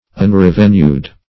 Unrevenued \Un*rev"e*nued\, a.
unrevenued.mp3